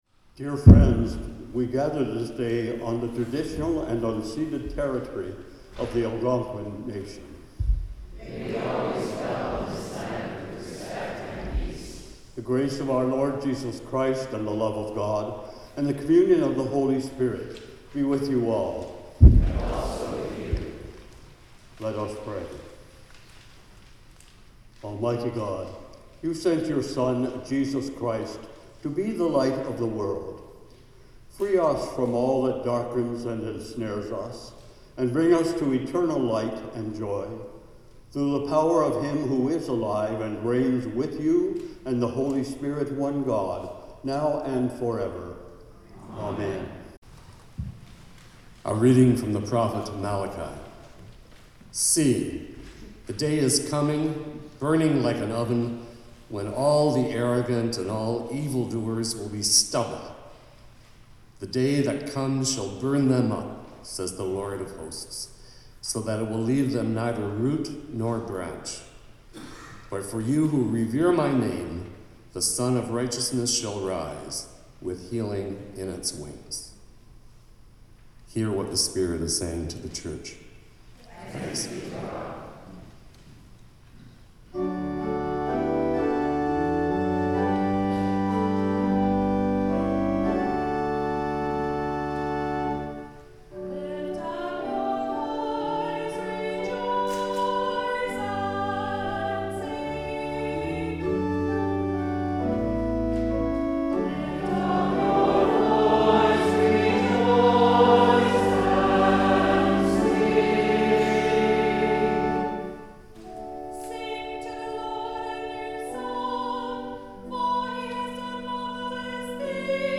TWENTY-THIRD SUNDAY AFTER PENTECOST Greeting, Land Acknowledgment & Collect of the Day First Reading: Malachi 4:1-2a Psalm 98: Lift up your voice, rejoice and sing Second Reading: 2 Thessalonians 3:6-13 (reading in French) Hymn 432: Jesus Calls Us! O’er the Tumult Gospel: Luke...